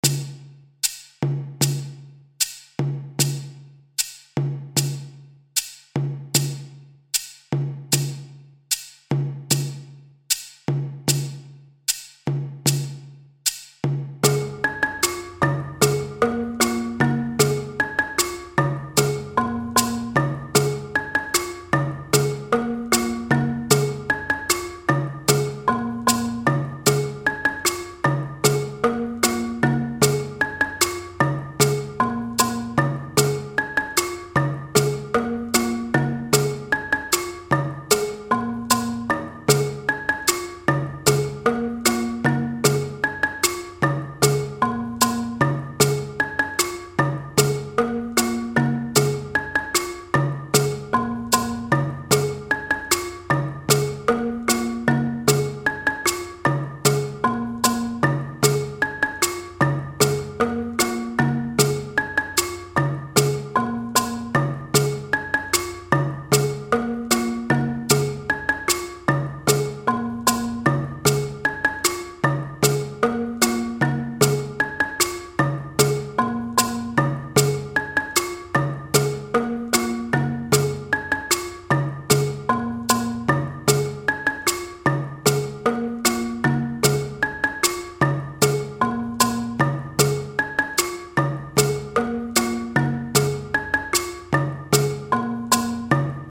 Pentatonische balafoon
152 bpm Patroon A
RitmeMuso-Bwe-152bpm-Patroon-A.mp3